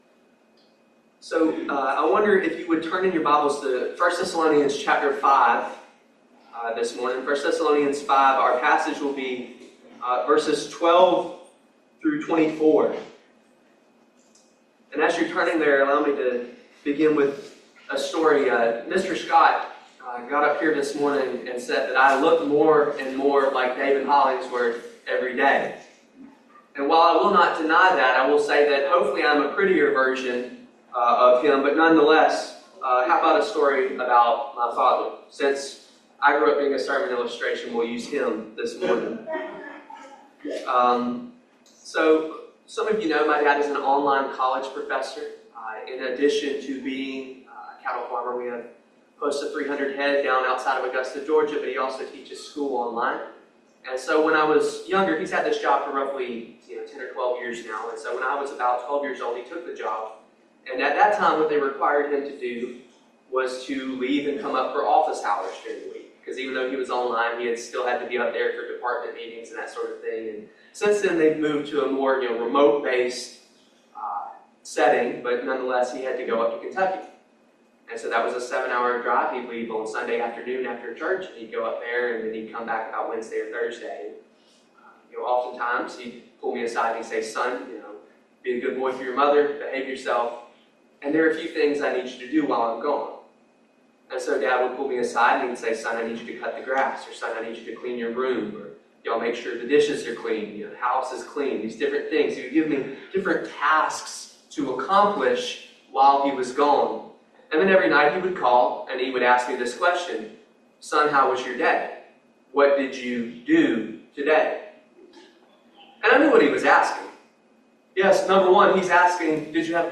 1 Thessalonians 5:12-24 Service Type: Family Bible Hour Practical Godly teaching on how to live as a Christian and hold onto His promises.